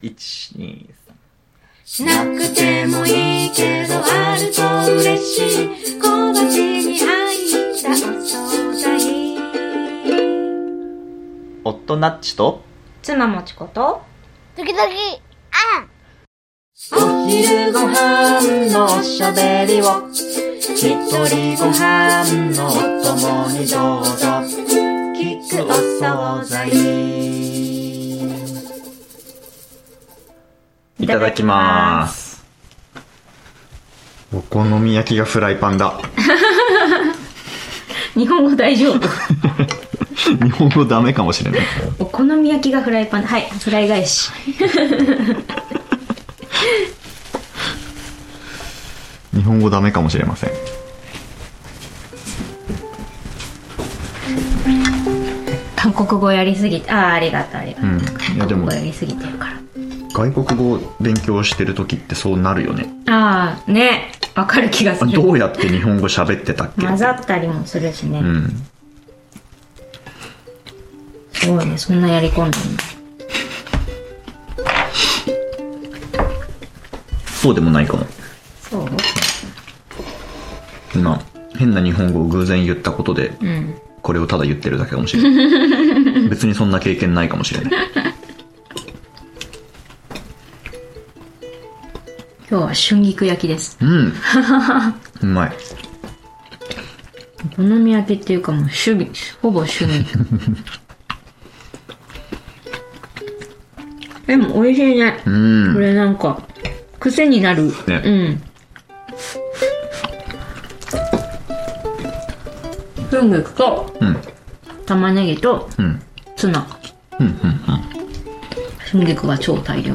フライパンでお好み焼きドーン！を食べながら、いつも以上にあちらこちらととっ散らかった雑談をしています。
Audio Channels: 1 (mono)